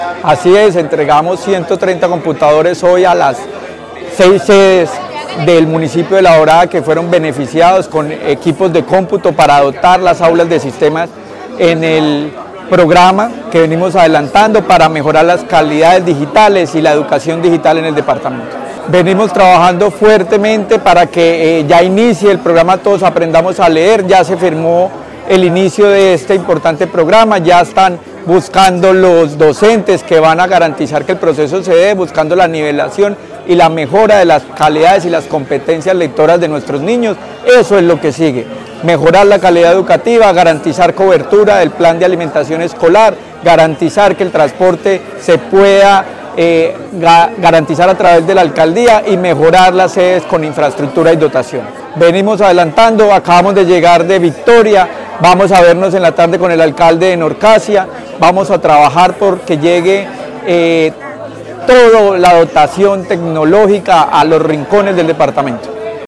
Luis Herney Vargas Barrera, secretario de Educación de Caldas